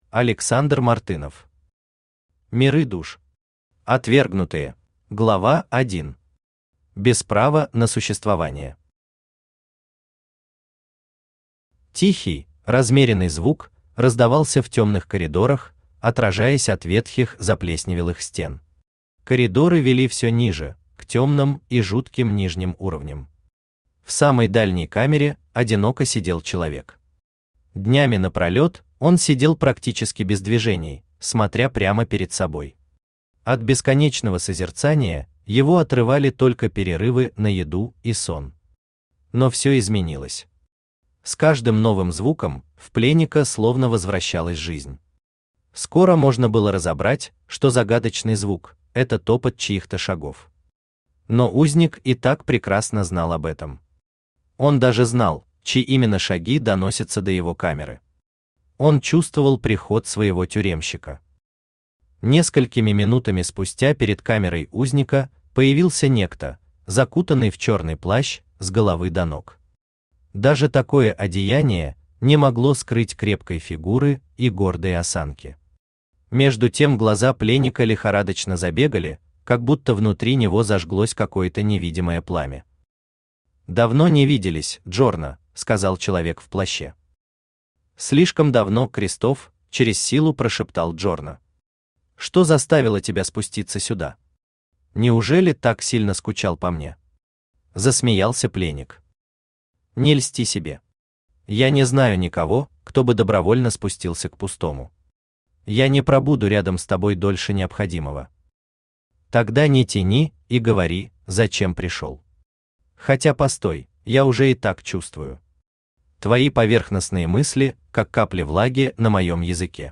Аудиокнига Миры Душ. Отвергнутые | Библиотека аудиокниг
Отвергнутые Автор Александр Мартынов Читает аудиокнигу Авточтец ЛитРес.